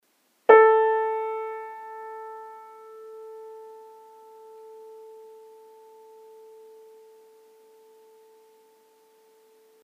Kawai_A.mp3